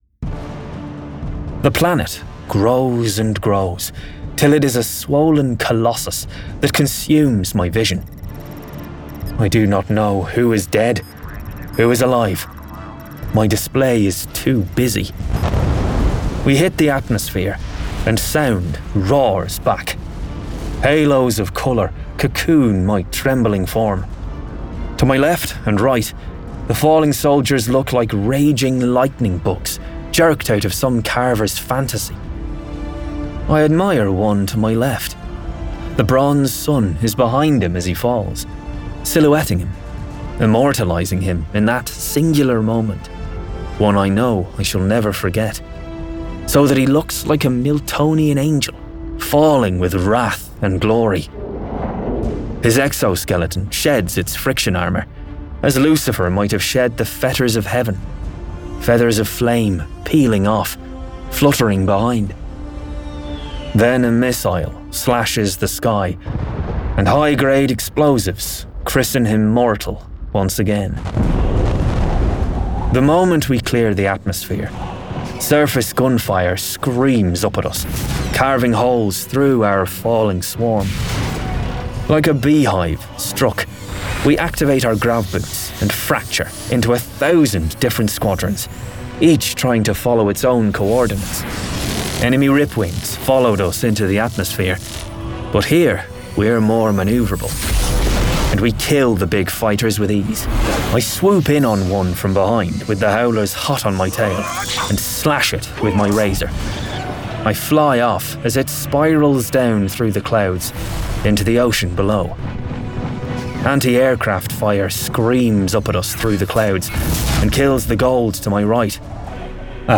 Full Cast. Cinematic Music. Sound Effects.
[Dramatized Adaptation]
Adapted from the novel and produced with a full cast of actors, immersive sound effects and cinematic music!